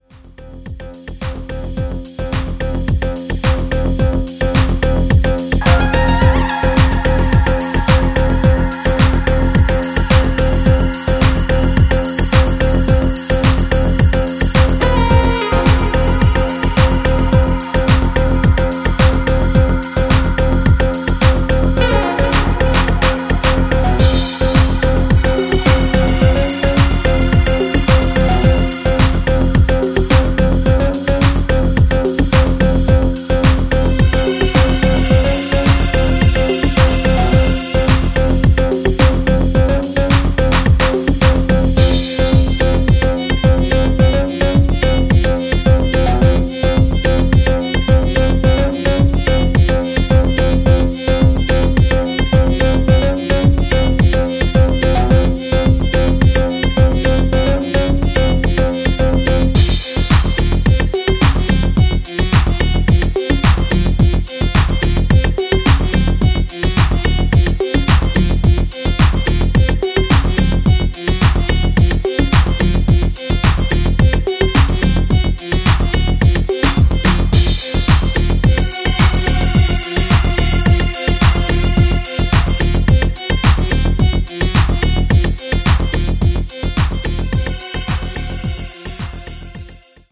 ダークなムードとルーディーなグルーヴでズブズブと怪しく引き込んでいく、なんとも痺れる仕上がりとなっています。